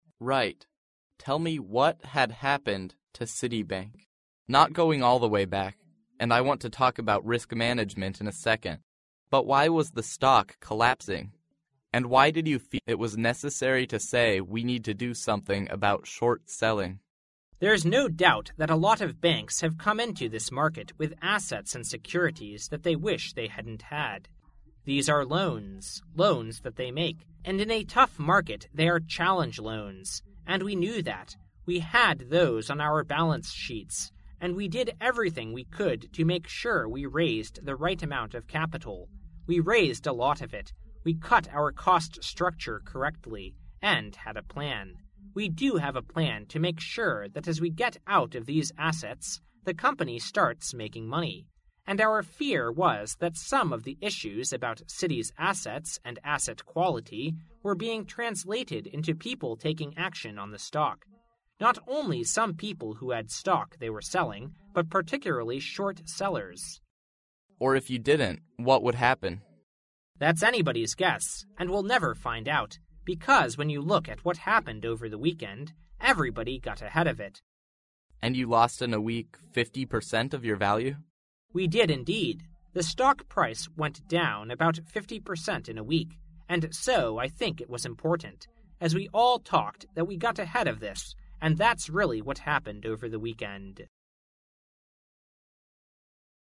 世界500强CEO访谈 第30期:花旗集团潘迪特 尽力确保增加股本总额(3) 听力文件下载—在线英语听力室
在线英语听力室世界500强CEO访谈 第30期:花旗集团潘迪特 尽力确保增加股本总额(3)的听力文件下载, 本栏目通过刁钻的提问和睿智的应答造就了绝佳的口语技巧，配以MP3与对应字幕，培养您用英文思维的能力，真正做到学以致用。